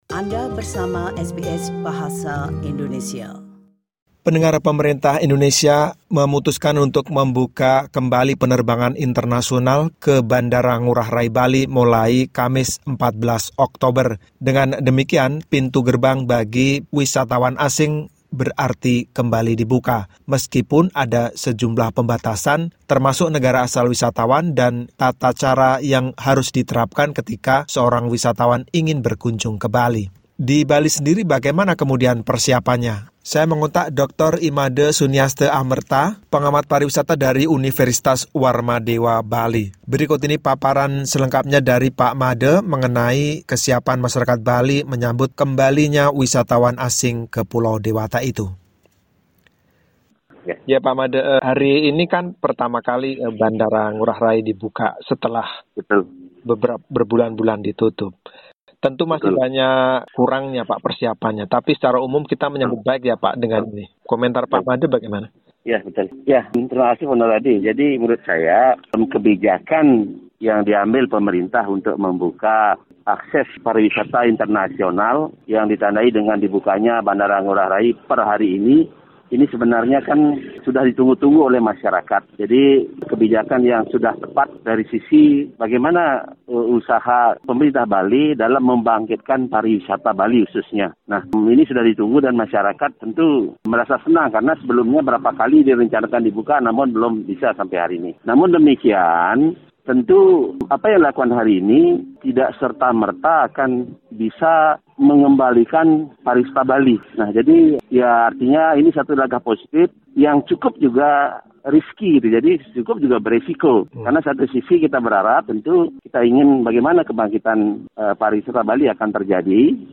Berikut ini wawancara selengkapnya